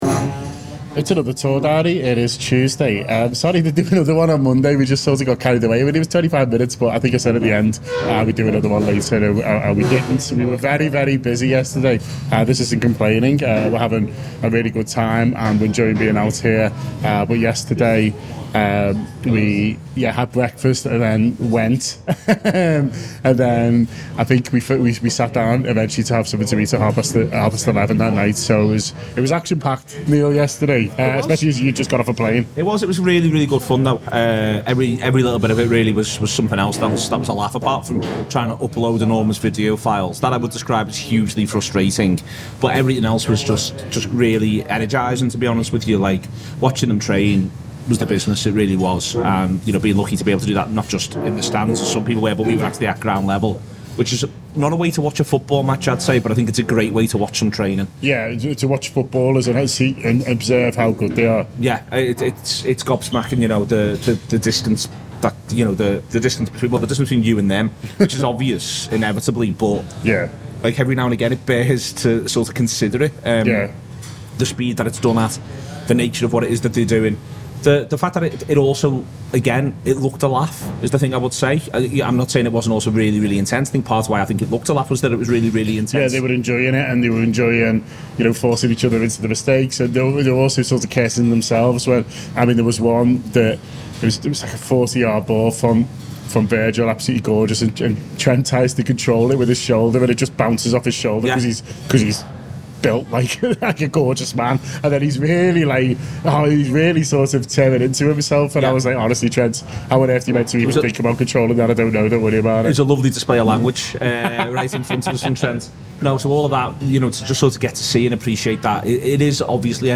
report from the Rajamangala Stadium…